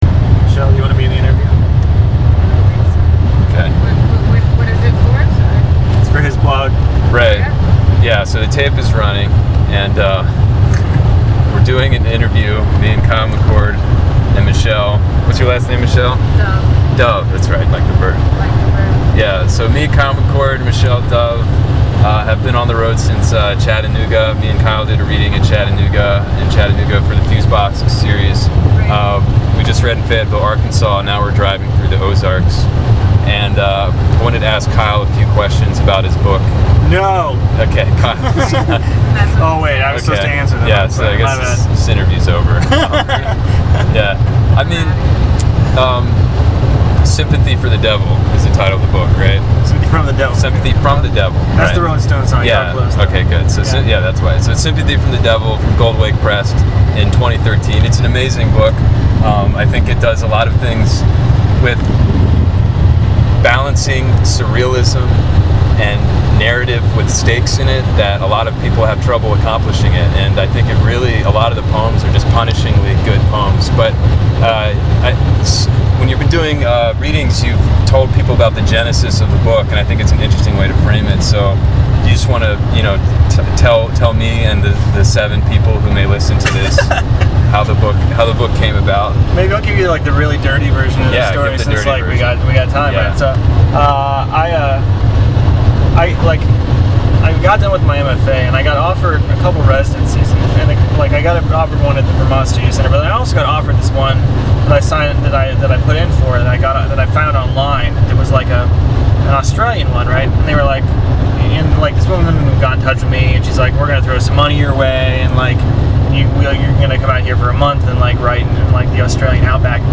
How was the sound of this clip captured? SftD is his third book of poems. I asked him some questions about it while we drove through the Middle South.